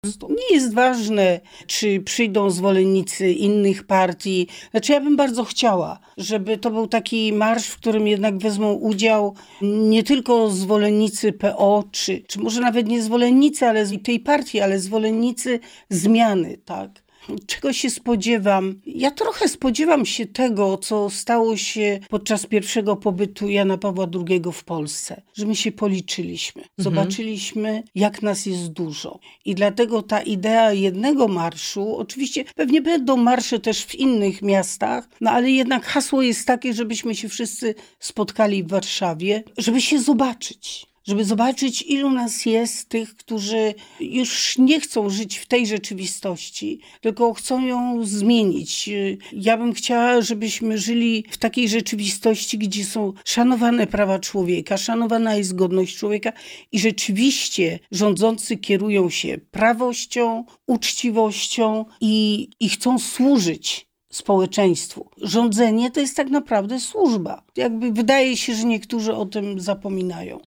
Europosłanka Janina Ochojska zapowiedziała swój udział wmarszu organizowanym przez Platformę Obywatelską 4 czerwca. O swoich oczekiwaniach oraz przyszłości politycznej rozmawiamy na antenie Radia Rodzina.